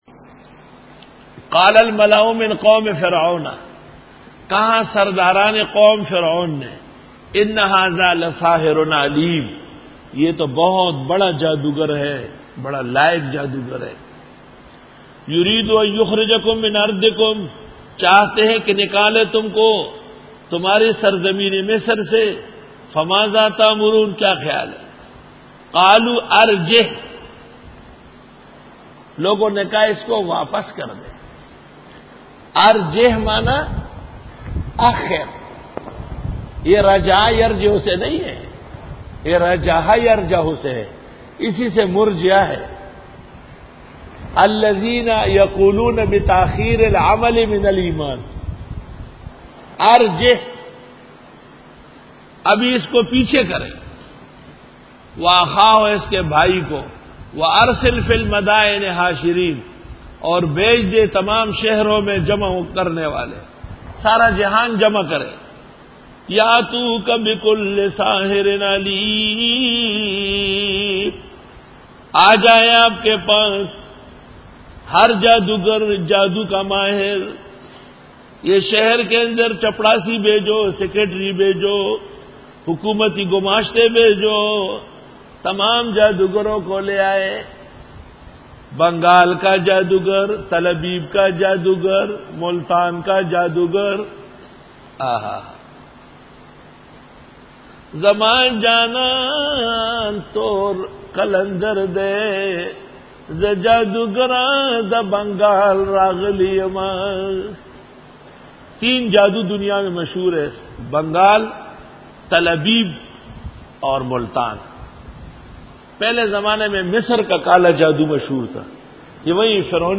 Dora-e-Tafseer